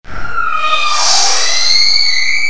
High pitch scream sounds(2)
Sound Effect
creature
scream
high pitch
Can be used for a creature death sounds
high_pitch_scream_gverb.mp3